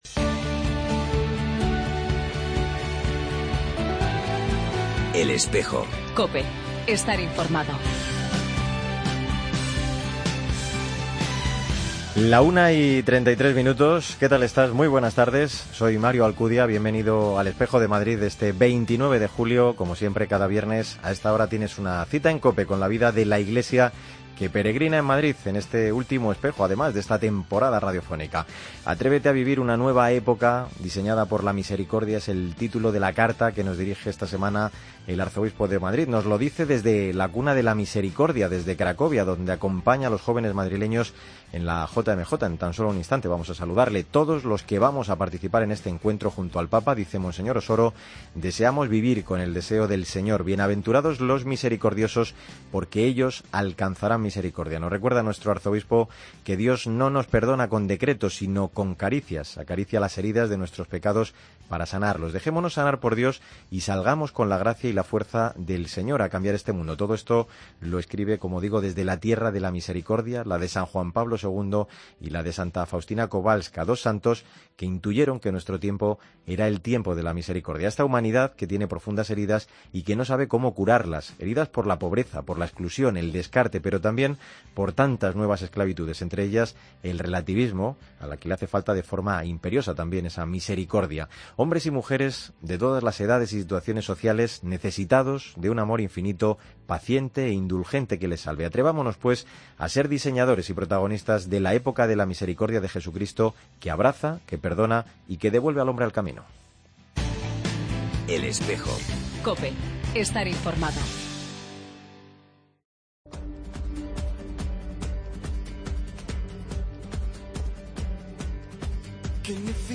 AUDIO: Entrevista al arzobispo de Madrid desde la JMJ de Cracovia y Familias Invencibles